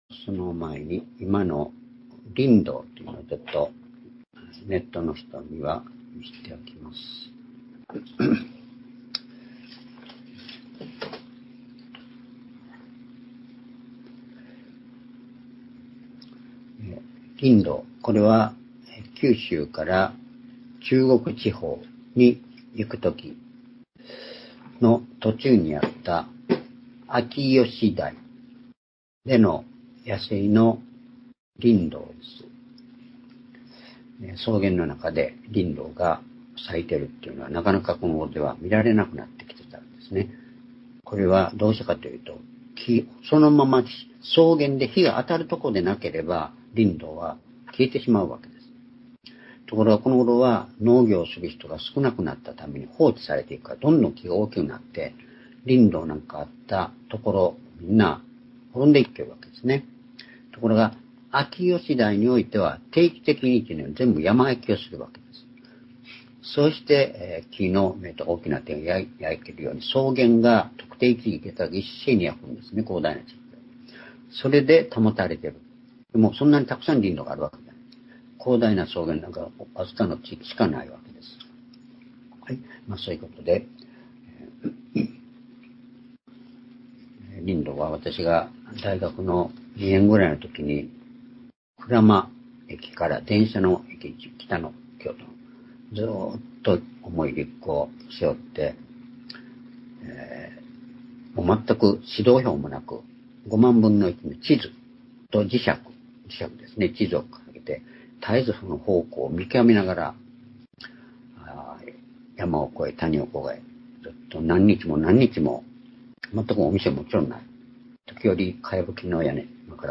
主日礼拝日時 2024年6月30日(主日) 聖書講話箇所 「主にあって思い起こす」 ヨハネ16章１節～４節 ※視聴できない場合は をクリックしてください。